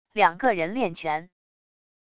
两个人练拳wav音频